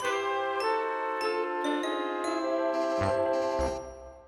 A song
Ripped from game